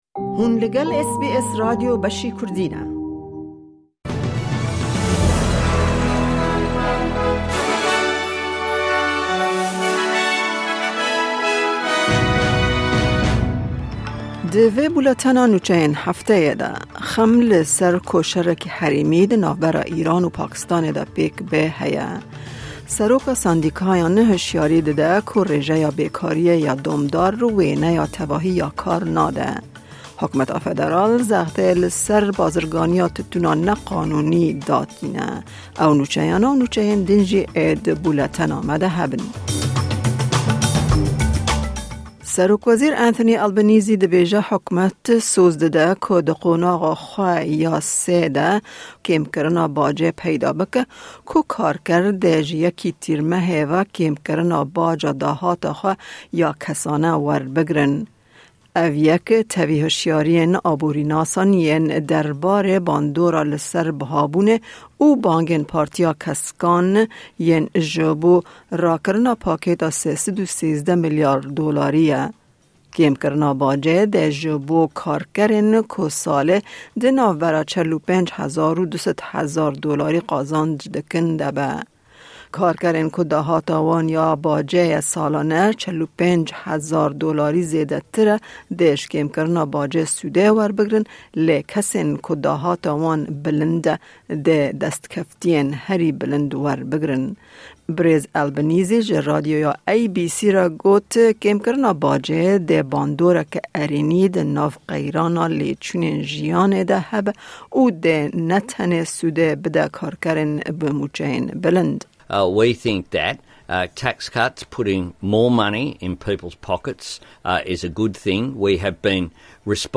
Nûçeyên Hefteyê